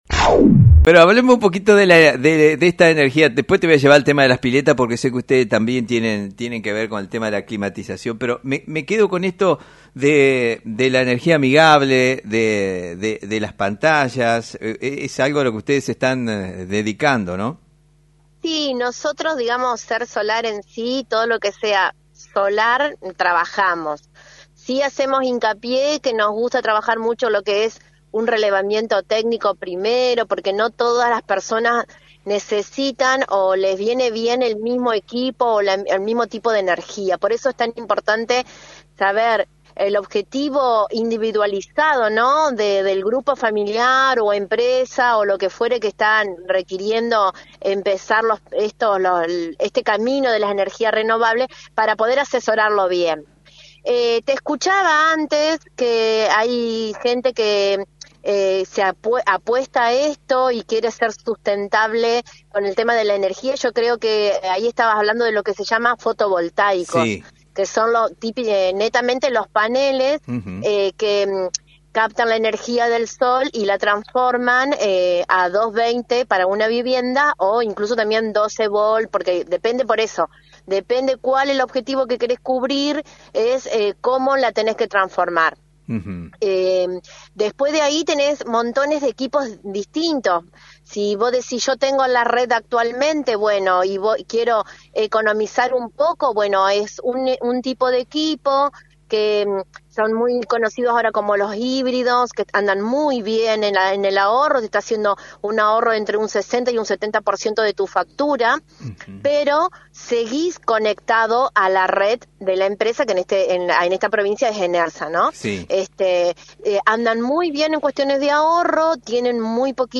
contó en diálogo radial con LT39 cómo llevan adelante proyectos de energía sustentable en hogares y empresas de la provincia.